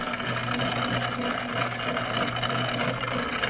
金属加工厂" 机床 金属切割器 磨刀机 滚筒 关闭4
Tag: 切割机 关闭 机器 金属 粉碎机